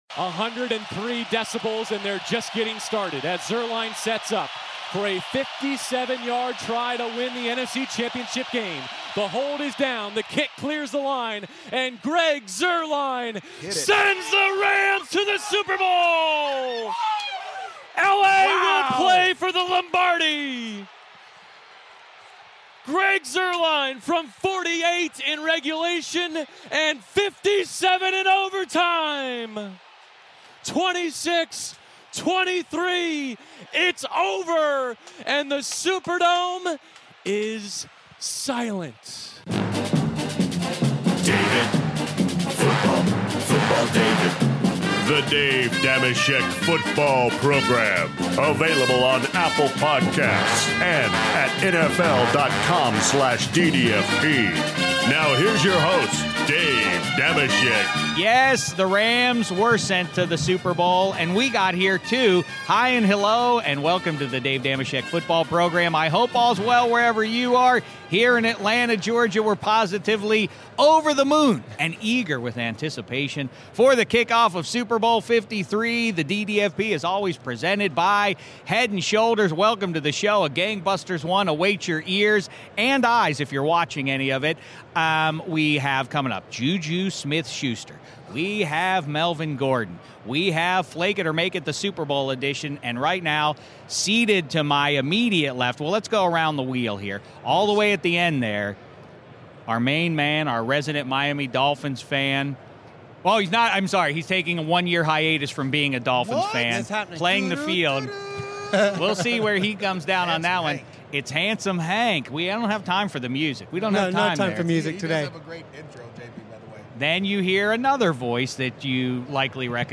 Dave Dameshek is still down on radio row in Atlanta getting you ready for the Patriots vs Rams Super Bowl LIII!